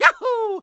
One of Mario's voice clips from Super Paper Mario.